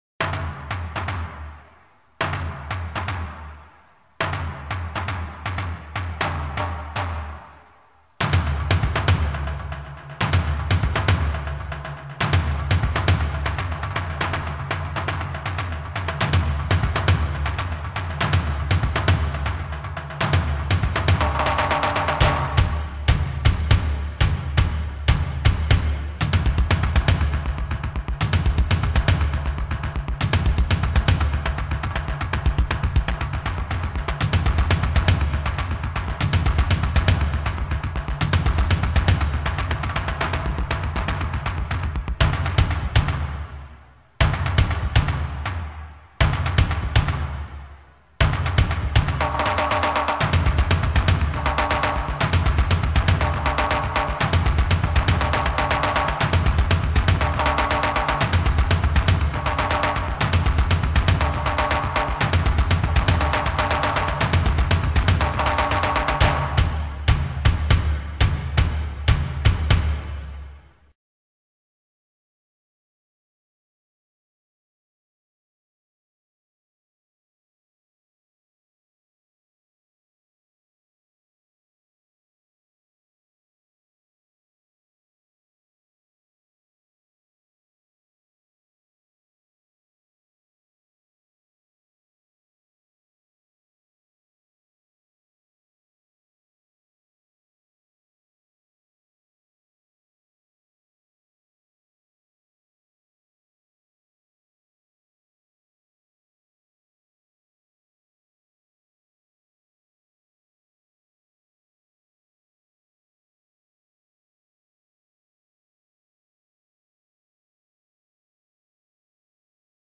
Eccovi alcune delle musiche che fanno parte del gioco.